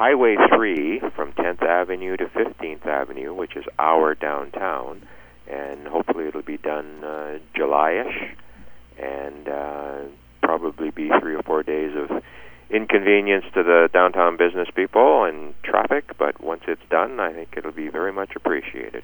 Mayor Ron Toyota says it may cause some inconveniences in the downtown.